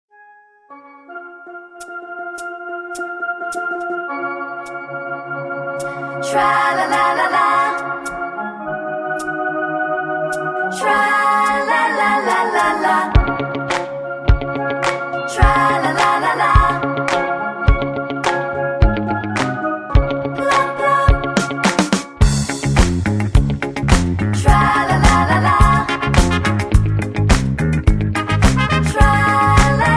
Tags: pop music , sound tracks , singers , reggae